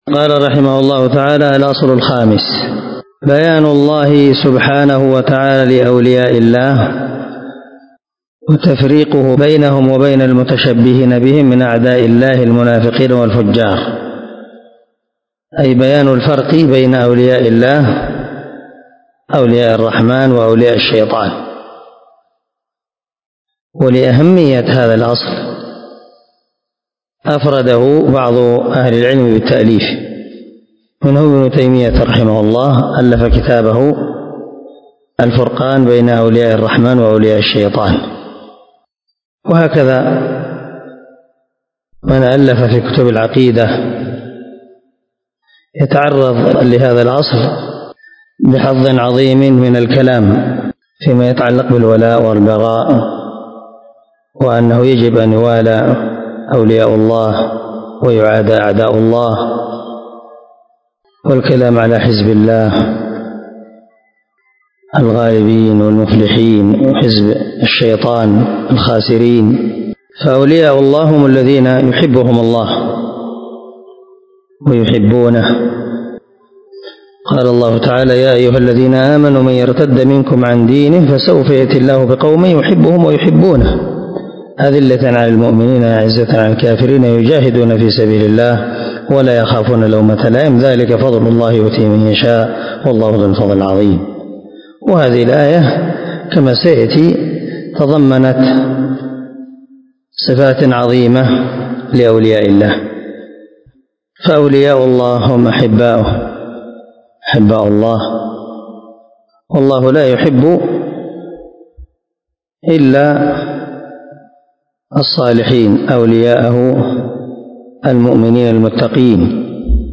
🔊 الدرس 9 من شرح الأصول الستة ( الأصل الخامس)